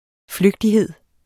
Udtale [ ˈfløgdiˌheðˀ ]